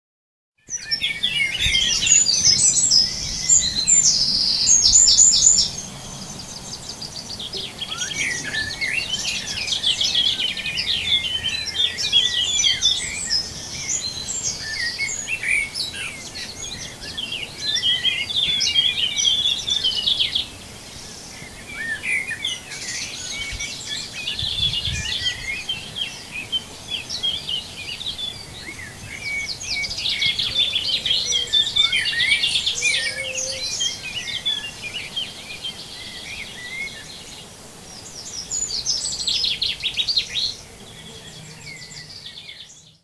pour entendre le concert
des oiseaux tôt le matin !